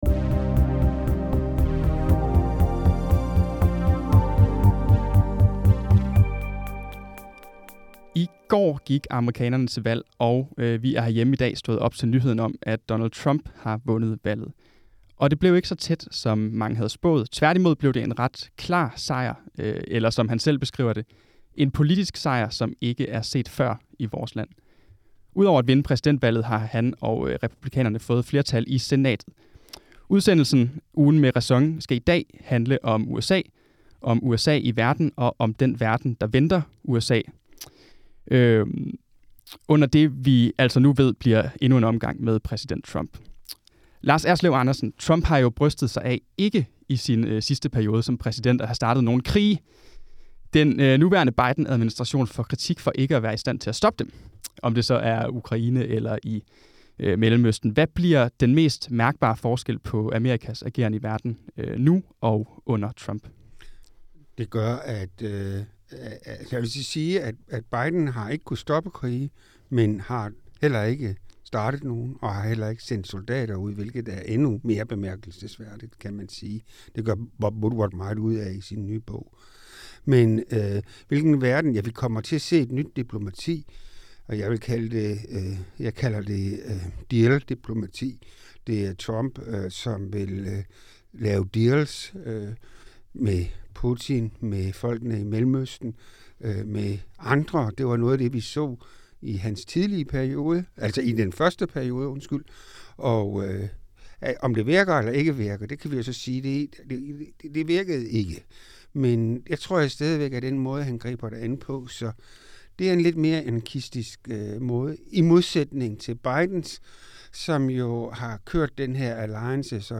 Ugen med RÆSON er vores uredigerede podcast, der hver onsdag – fra vores redaktion ved Rådhuspladsen i København – stiller tidens store spørgsmål til nogle af RÆSONs skarpeste skribenter.